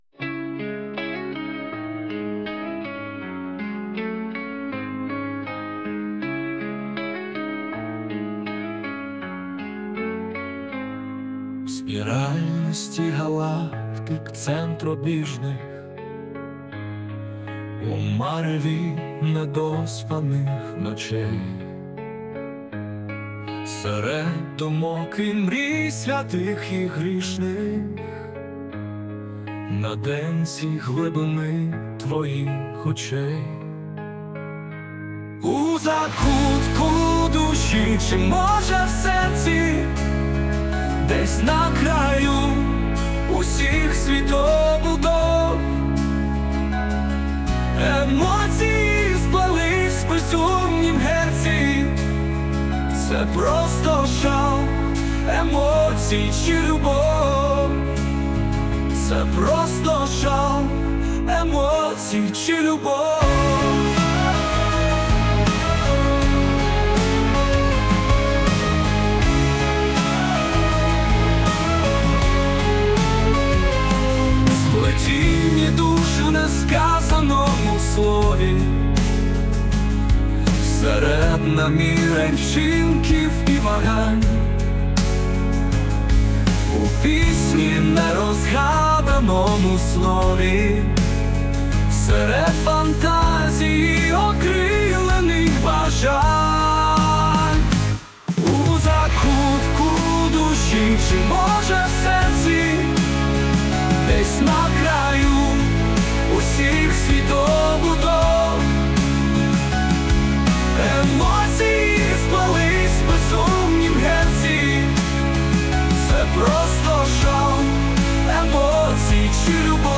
Пісня створена за допомогою ШІ.
СТИЛЬОВІ ЖАНРИ: Ліричний